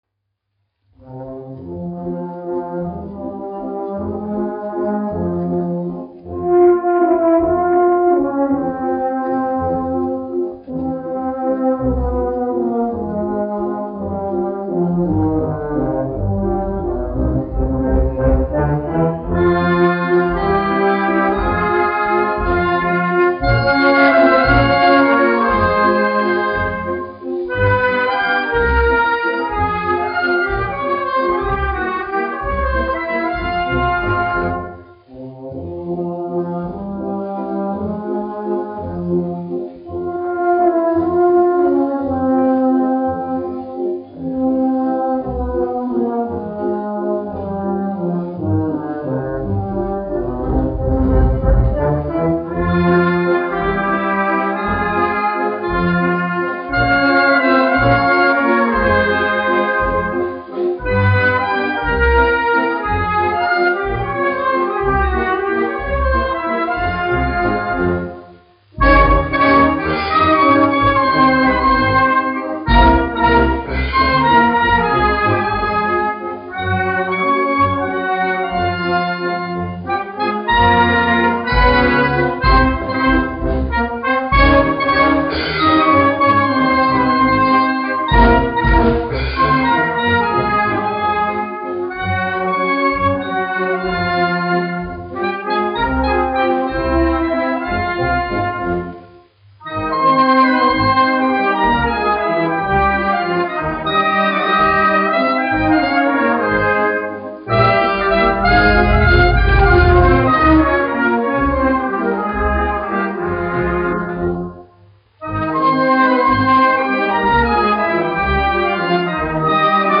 1 skpl. : analogs, 78 apgr/min, mono ; 25 cm
Pūtēju orķestra mūzika
Skaņuplate